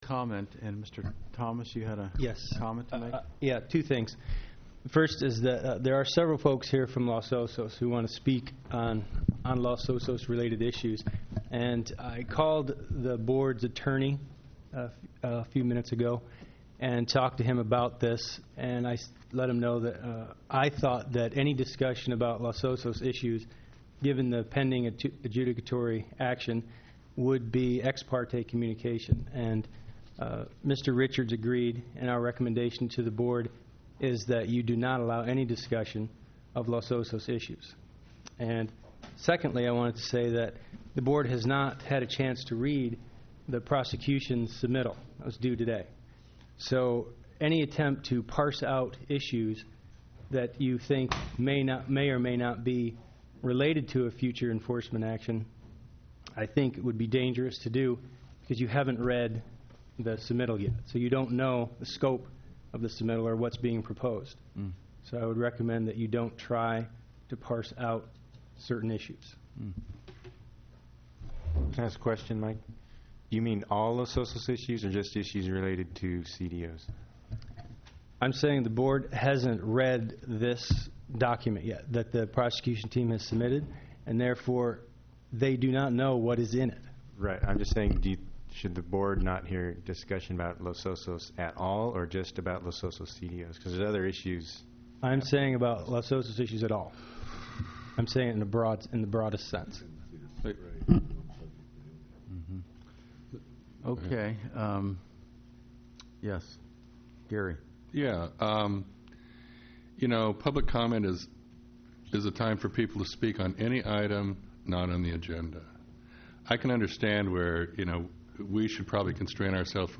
j_item20_public_forum.mp3